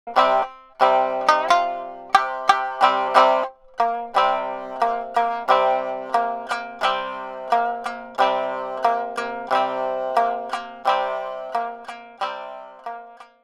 Traditional fishing folk song (Minyo) for shamisen.
Rhythm